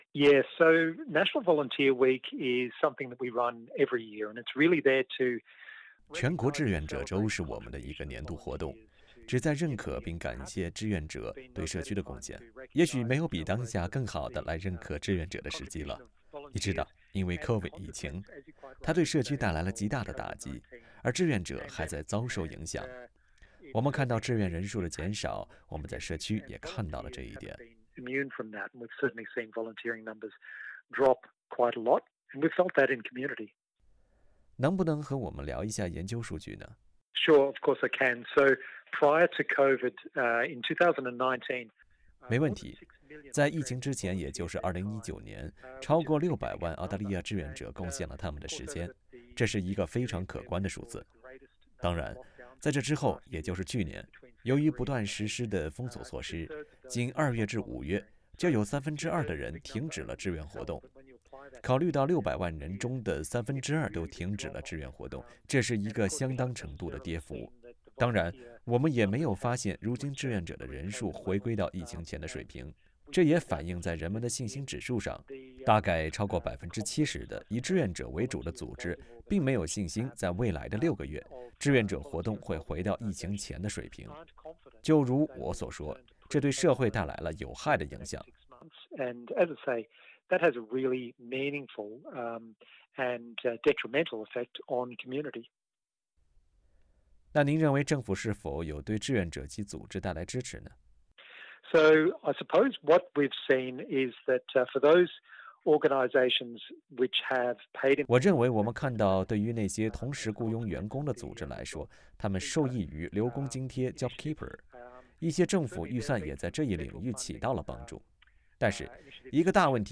歡迎點擊圖片音頻，收聽有關全國志願者週的完整寀訪。